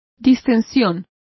Complete with pronunciation of the translation of distension.